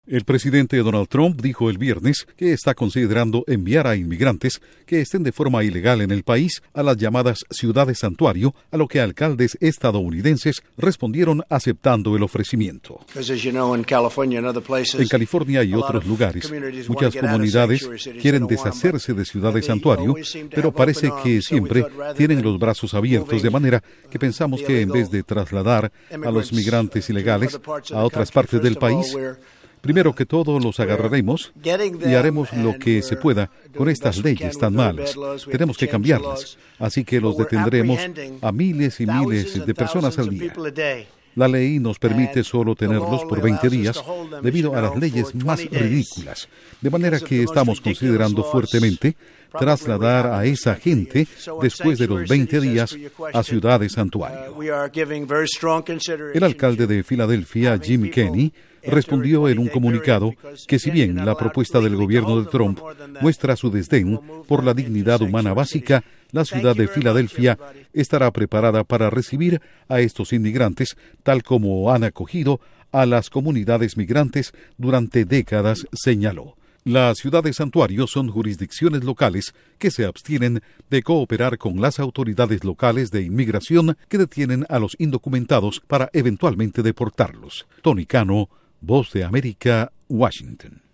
Duración: 1:30 Con declaraciones de Donald Trump/Presidente de EE.UU.